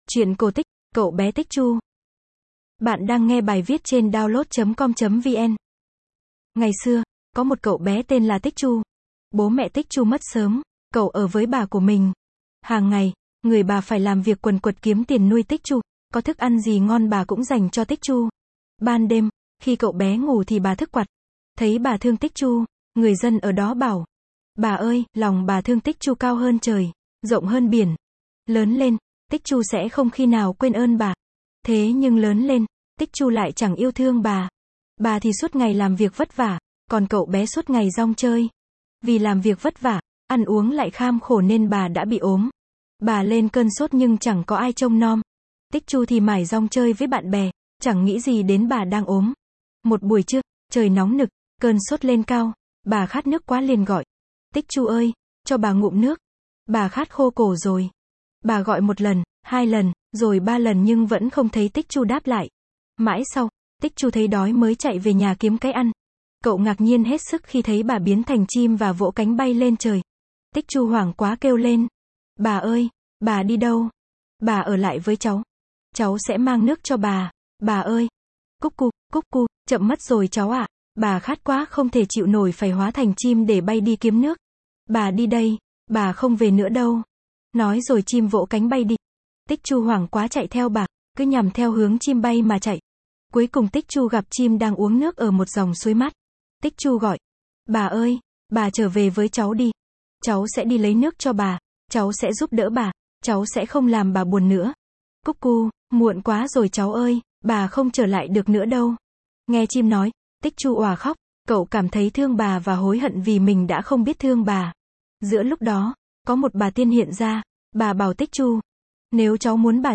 Sách nói | Cậu bé tích chu